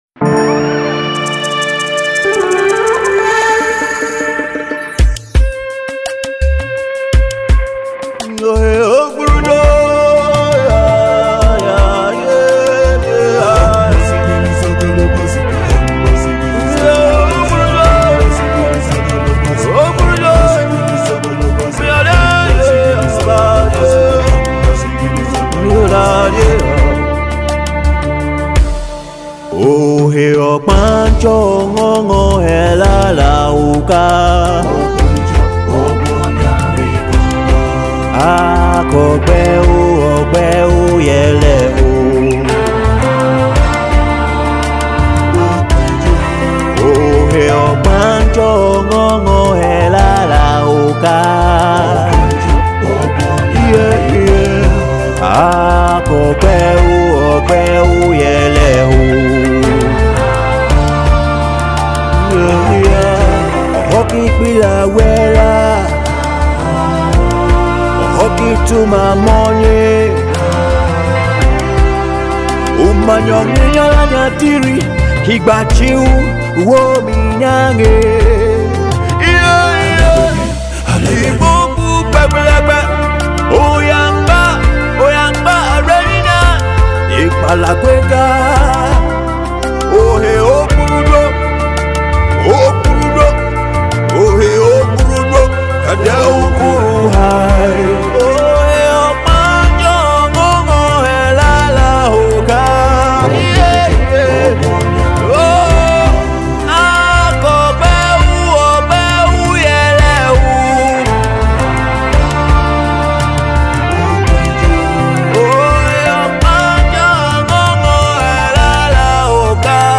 soul-stirring new single
Award-winning Gospel Artist